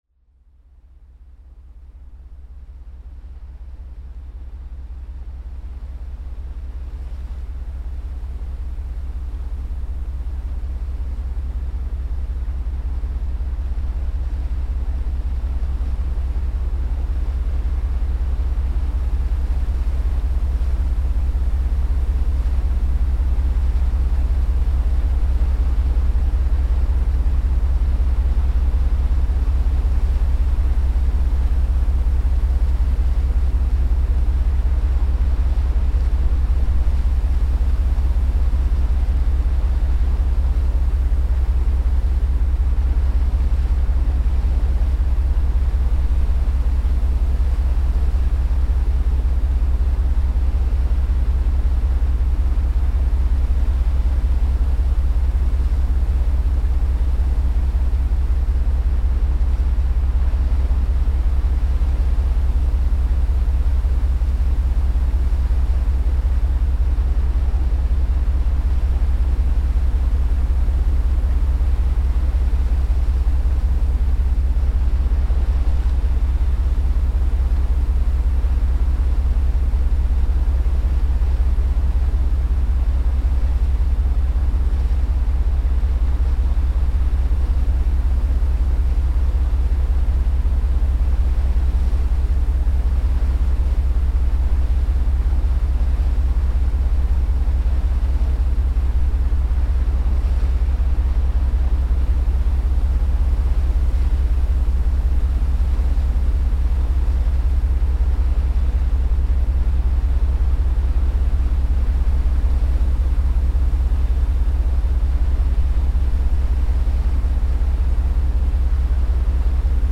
July 2017 After a bird and marine mammal offshore survey, our ship was slowly sailing back to the harbour through an eerie almost surreal marine landscape. This an excerpt of a long duration recording.
PFR12960, 13/05/2017, deck ambience, North Sea, offshore